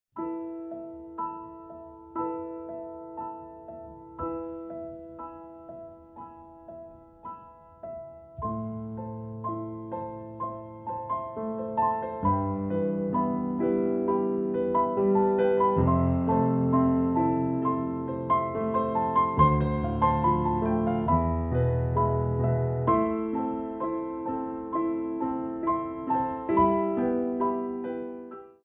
Compositions for Ballet Class
Exercice préparatoire